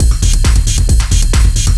DS 135-BPM B1.wav